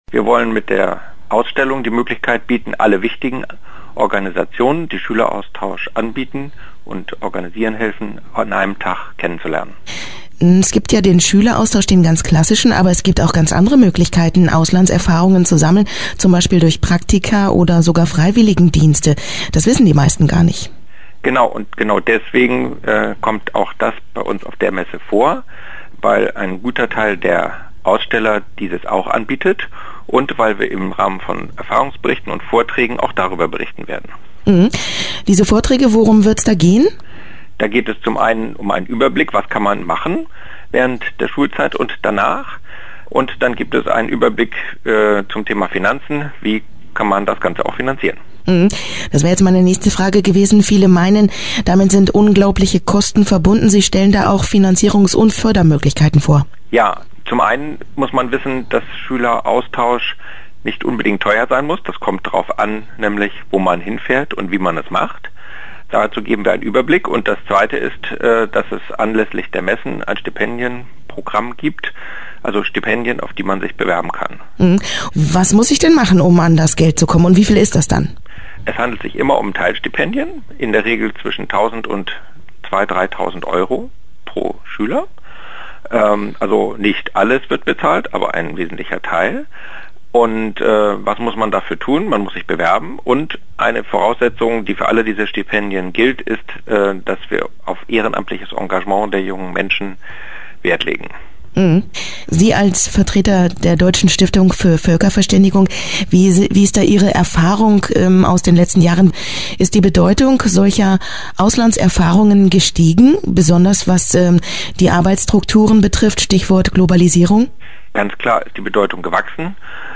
Interview-Schueleraustausch-BS.mp3